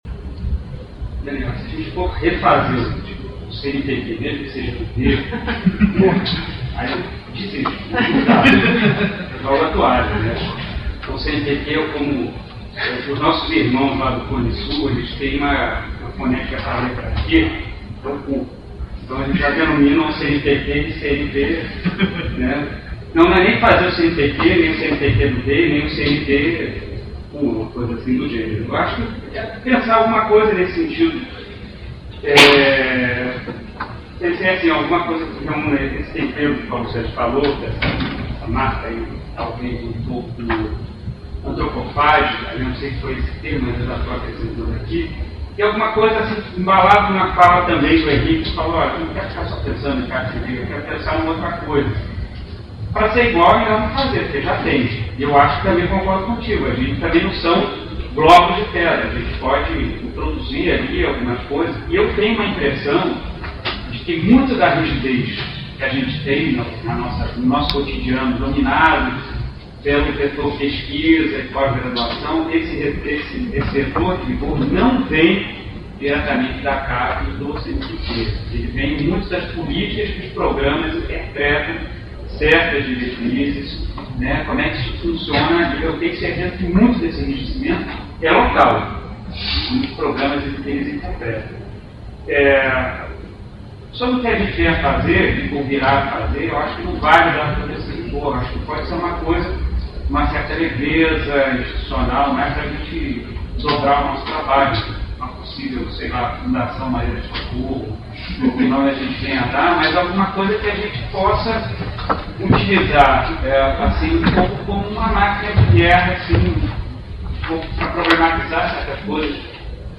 Atorede 2012 - Encontro Anual de Estudos CTS (Ci�ncia - Tecnologia - Sociedade) - 24 e 25/11/2012
debate_sabado_parte4.mp3